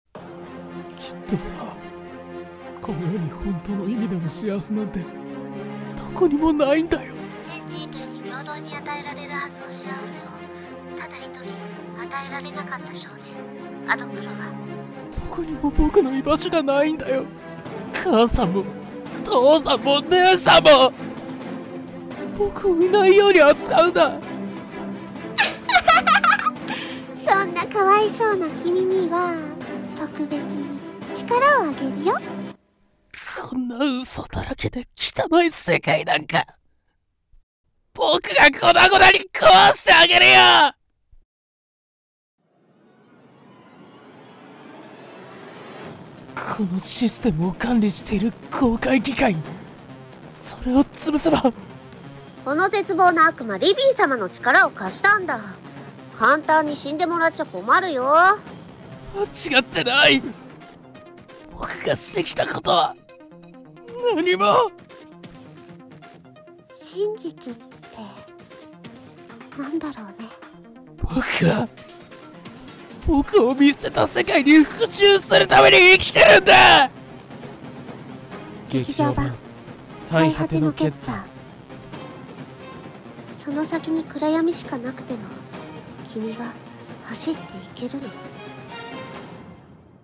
【声劇台本】
(二人用)